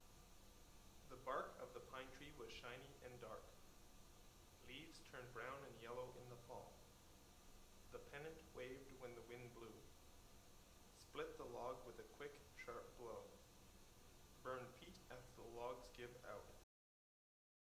How much the headphones attenuate the voice of a person talking to you when background noise is present.
Male Voice 2
male-voice-2-sample.wav